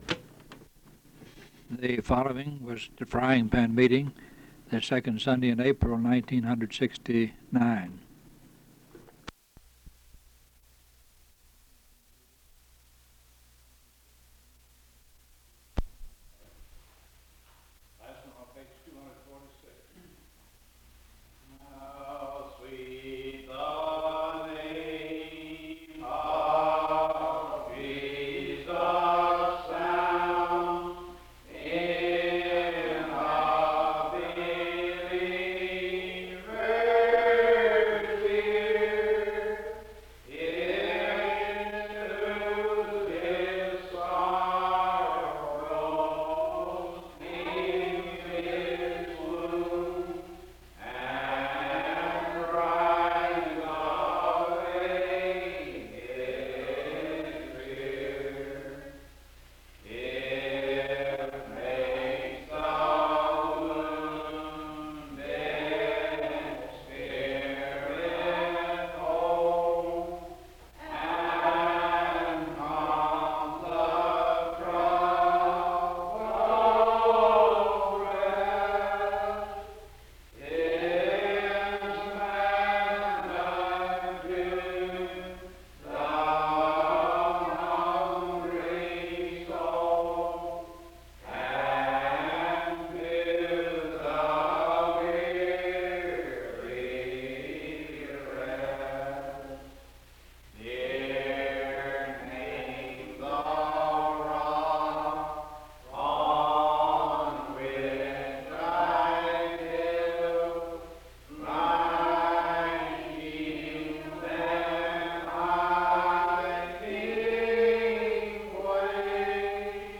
Herndon (Va.)